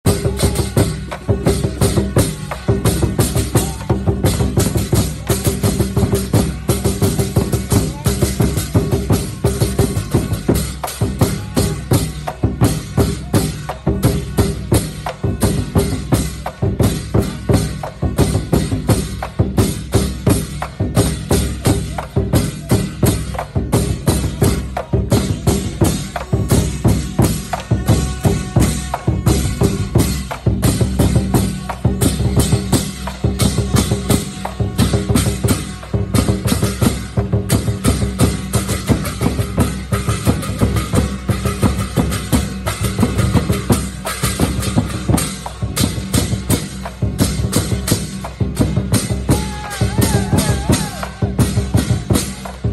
Tiếng đánh Trống, đánh Chiêng cổ vũ đều đặn
Thể loại: Tiếng động
Description: Tiếng đánh trống, tiếng chiêng cổ vũ vang đều đều, nhịp nhàng tạo nên không khí sôi động và hào hứng.
tieng-danh-trong-danh-chieng-co-vu-www_tiengdong_com.mp3